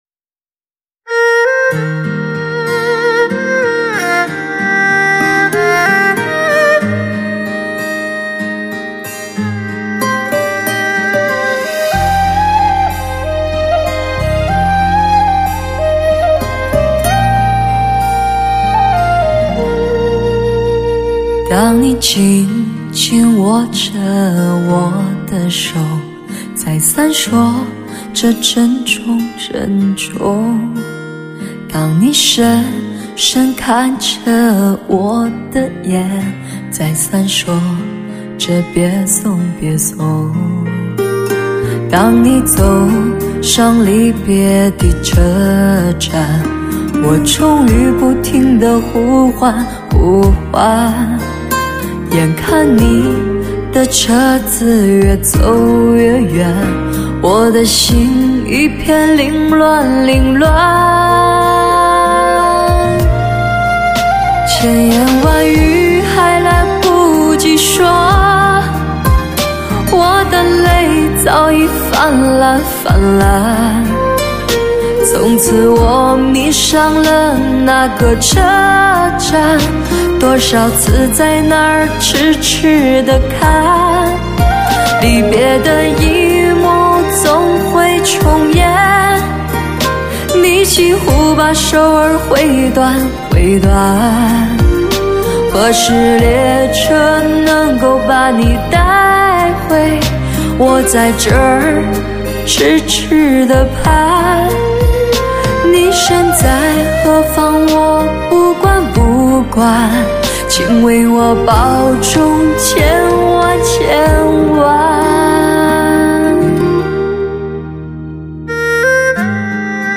，发烧人声，流行经典，曲曲动听。
不容错过的[HI-FI]试音碟。